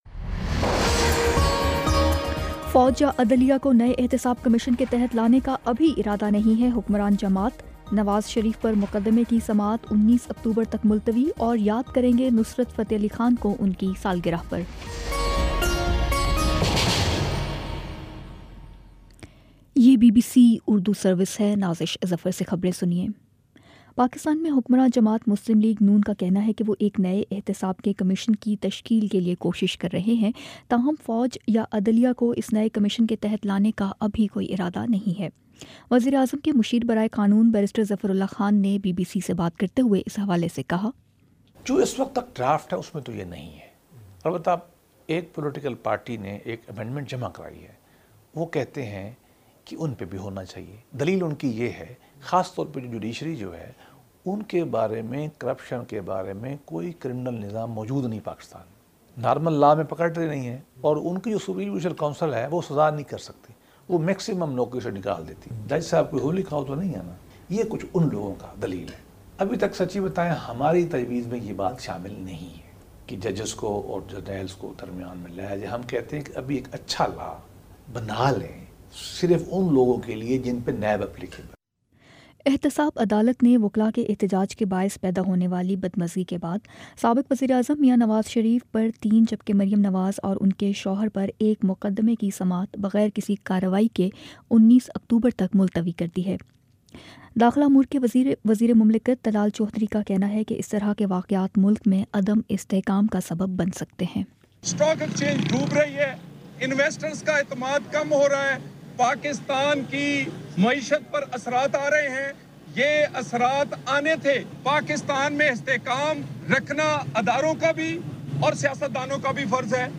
اکتوبر 13 : شام چھ بجے کا نیوز بُلیٹن